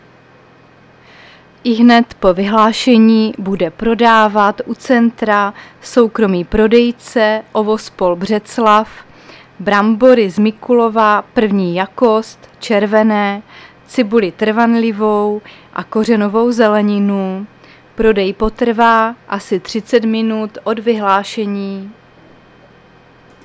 Záznam hlášení místního rozhlasu 14.8.2023